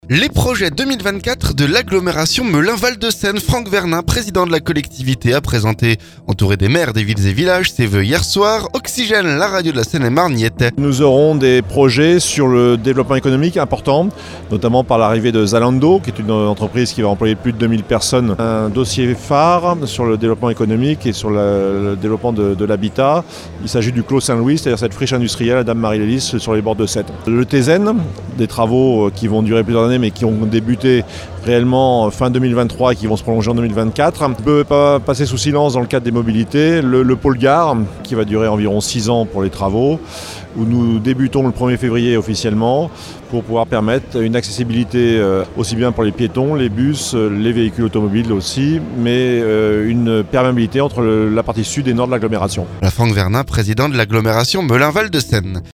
Les projets 2024 de l'agglomération Melun Val de Seine. Franck Vernin, président de la collectivité, a présenté, entouré des maires des villes et villages, ses vœux mardi soir. Oxygène, la radio de la Seine-et-Marne y était.